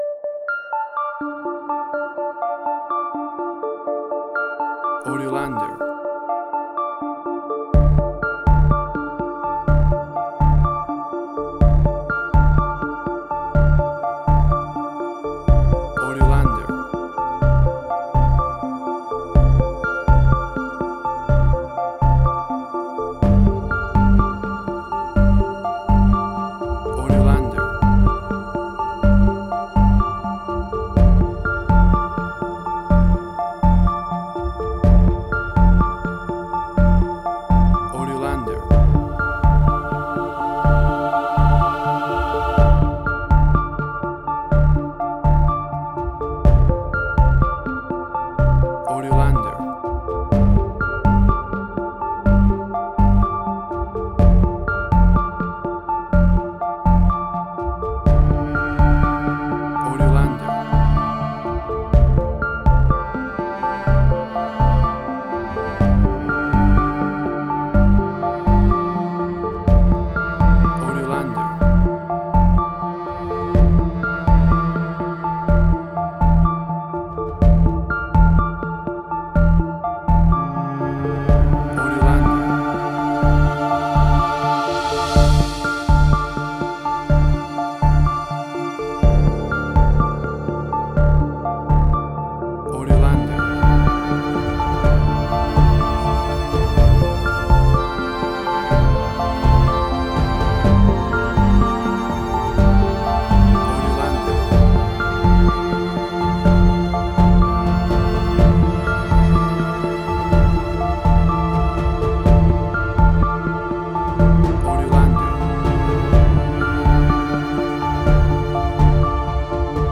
Modern Science Fiction Film, Similar Tron, Legacy Oblivion.
Tempo (BPM): 124